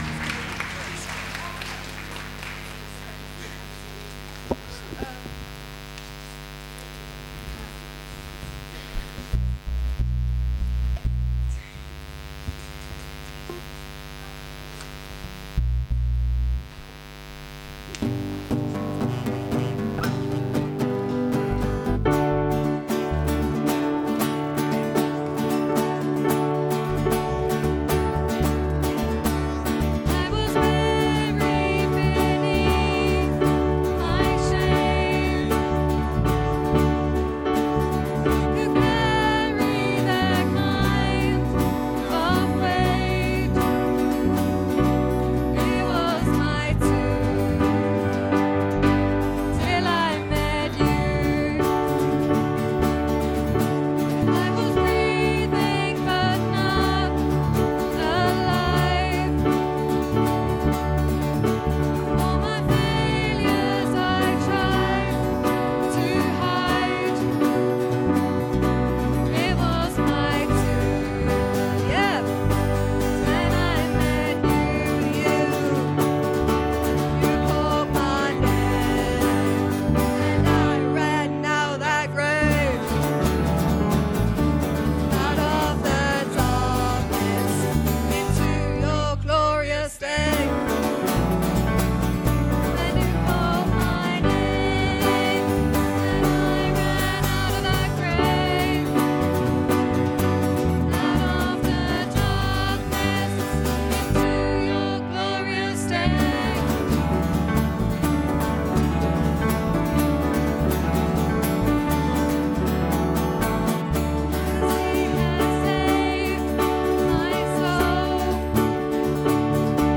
Youth led service - Sittingbourne Baptist Church
Service Audio